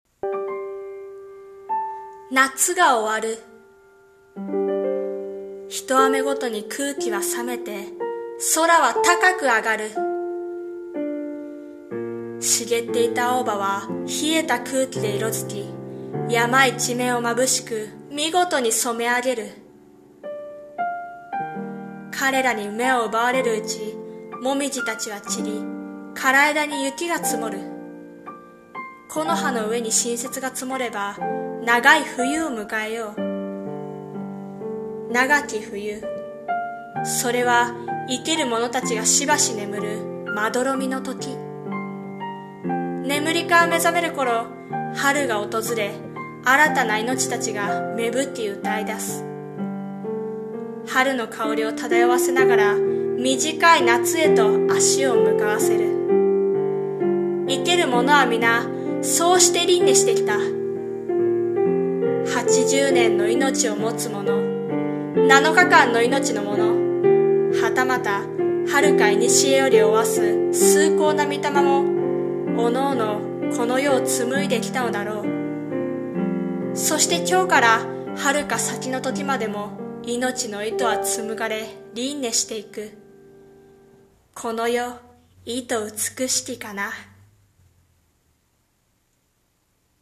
【和風台本】【朗読台本】